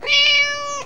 meow1.wav